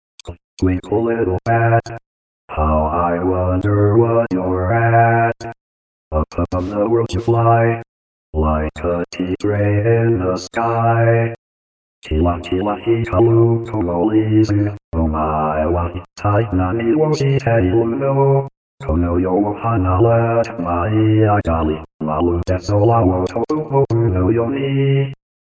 iSpeechというテキスト読み上げソフトをダウンロードして、しゃべらせてみた。
そしたら勝手に歌い出した。
ちなみに普通にしゃべる声も入ってはいるけれど。